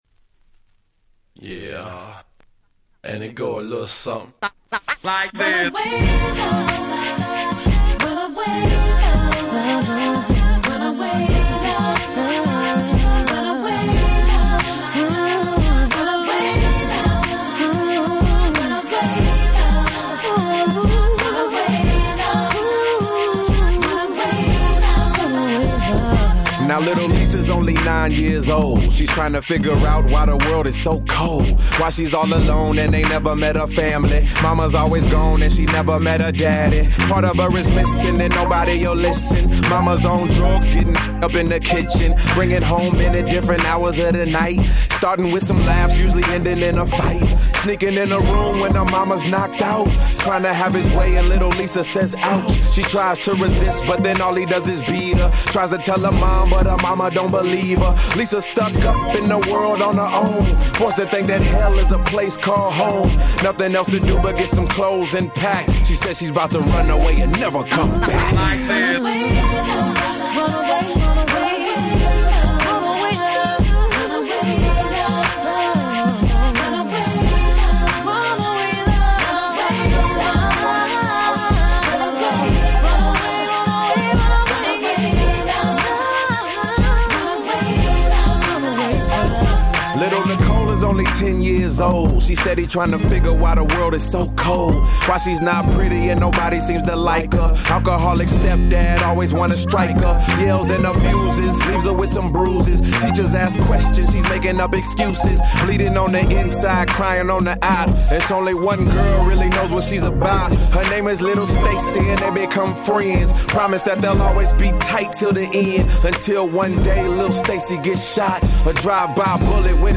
06' Super Hit Hip Hop !!